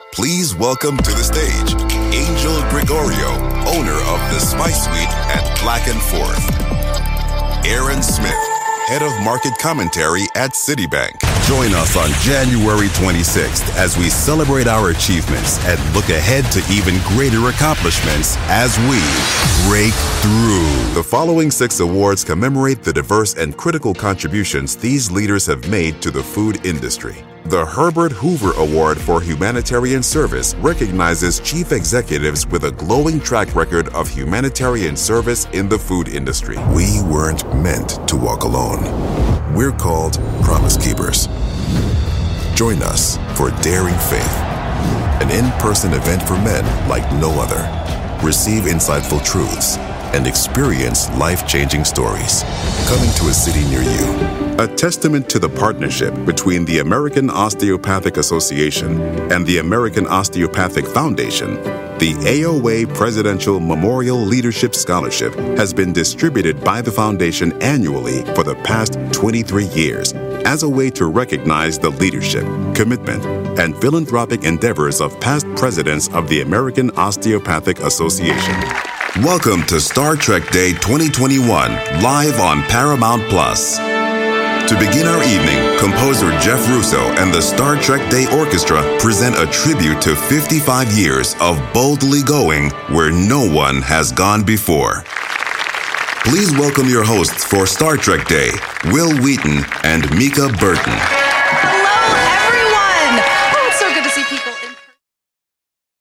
Anglais (américain)
Annonces